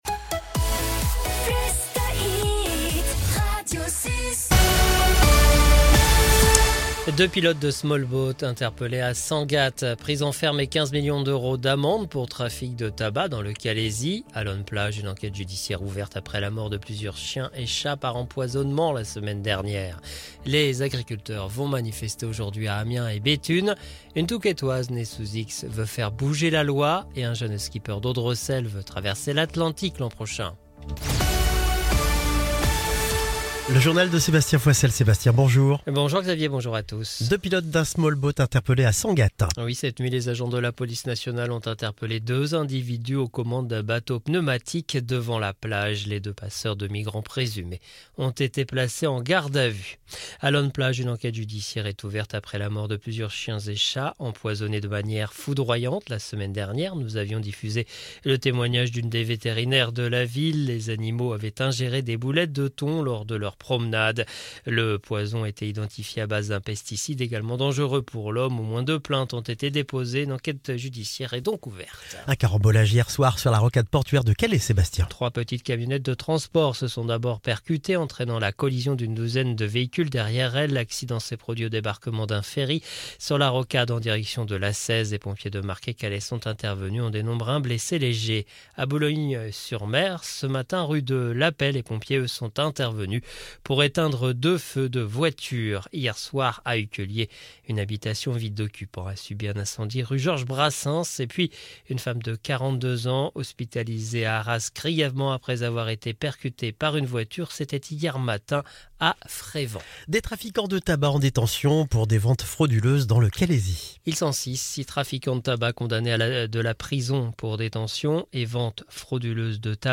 Le journal du mercredi 4 décembre 2024